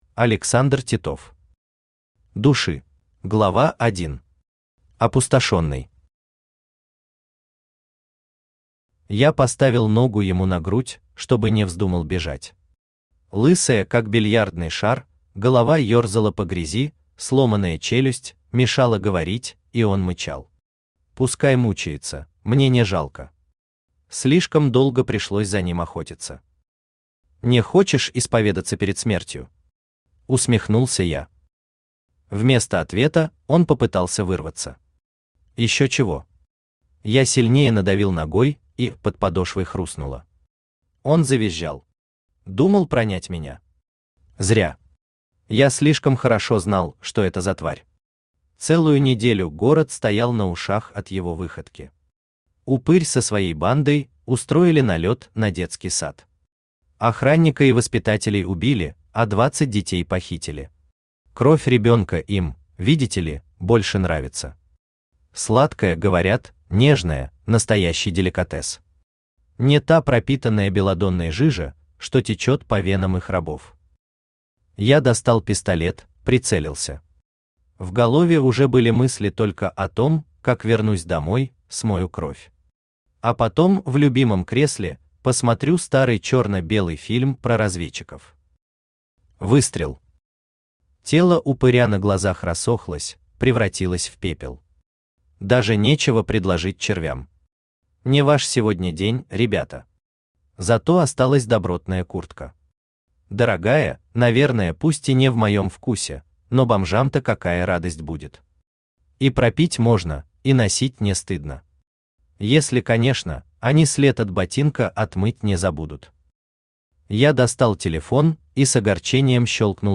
Аудиокнига Души | Библиотека аудиокниг
Aудиокнига Души Автор Александр Титов Читает аудиокнигу Авточтец ЛитРес.